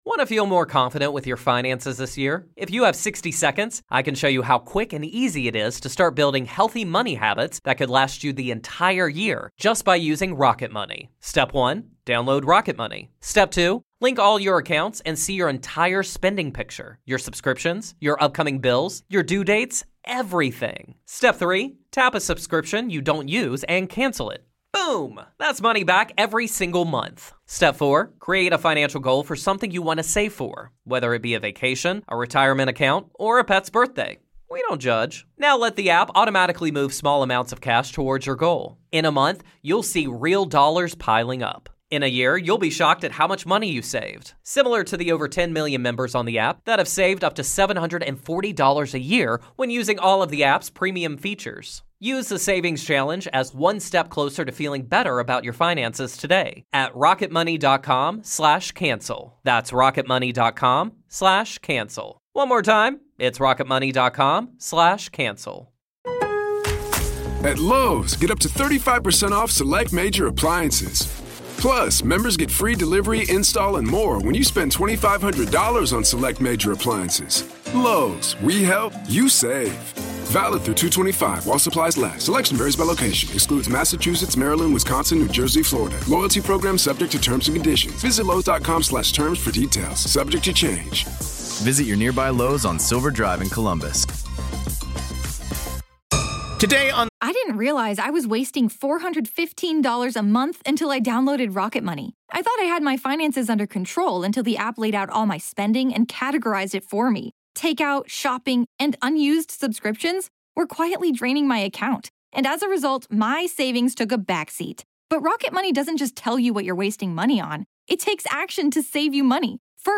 LISTEN HERE In part two of our interview